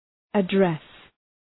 address Προφορά
{ə’dres, ‘ædres}